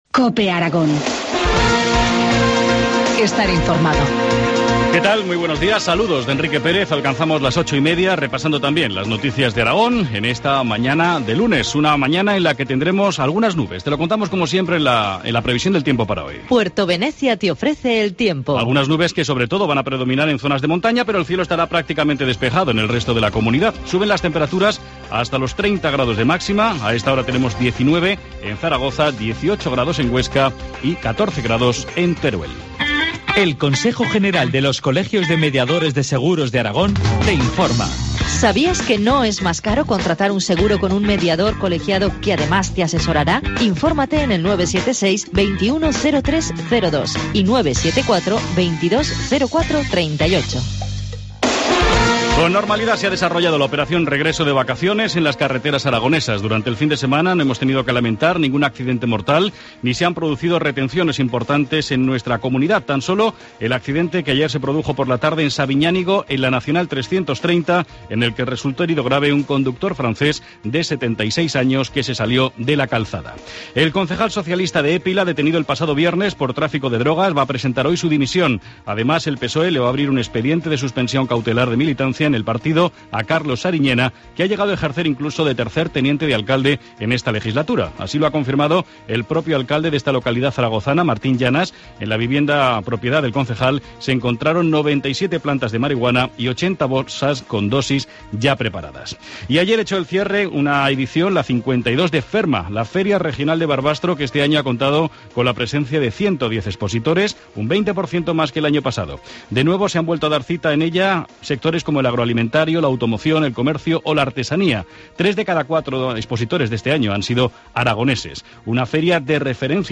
informativo matinal, lunes 2 de septiembre, 8.25 horas